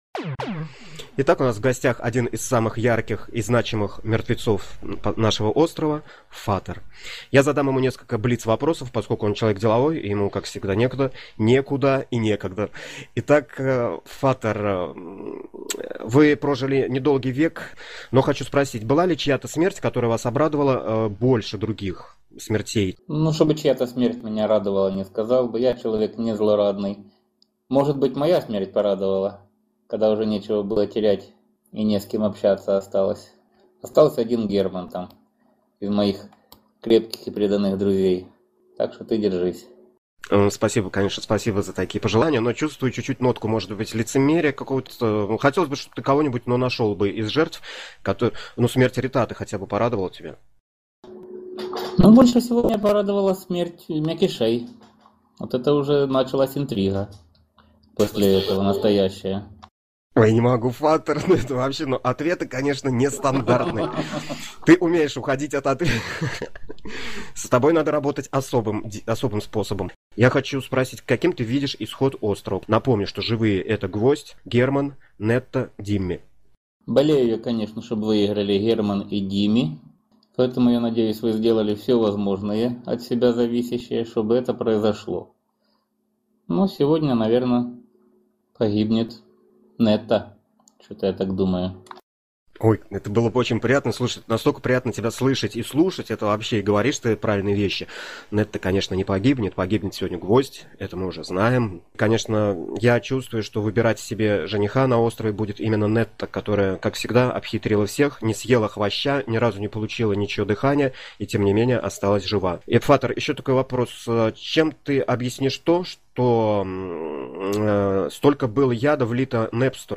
Интервью